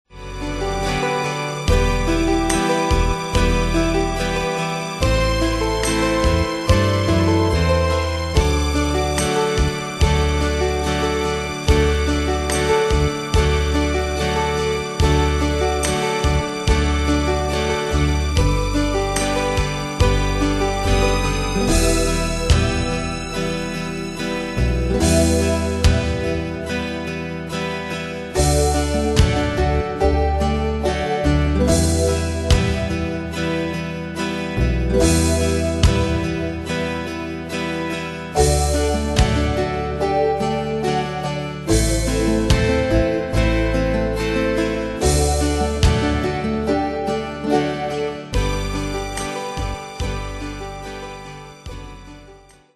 Style: PopFranco Ane/Year: 1980 Tempo: 73 Durée/Time: 3.24
Danse/Dance: Ballade Cat Id.
Pro Backing Tracks